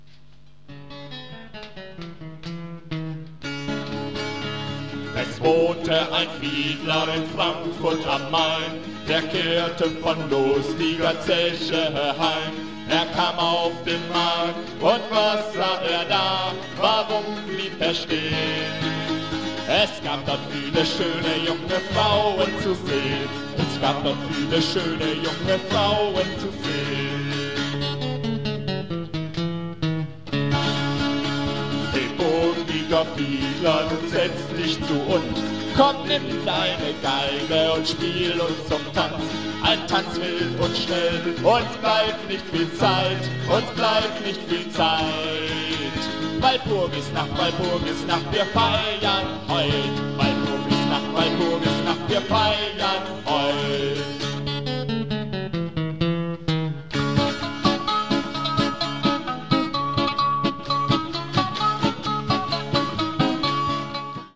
Die eingestellten Hörbeispiele sind also immer live eingespielt - entweder in normale Kassettengeräte - oder bestenfalls in Mischpulte mit Effekten. Die so entstandene mindere Klangqualität bitte ich zu entschuldigen